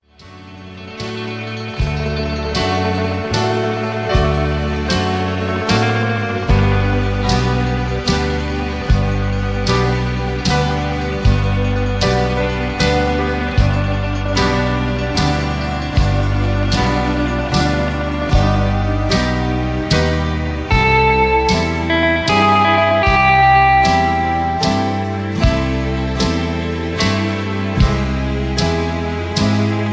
backing tracks , karaoke